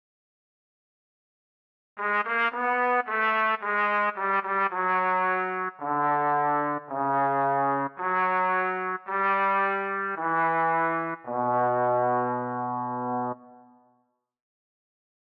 Key written in: B Major
Type: Barbershop
Each recording below is single part only.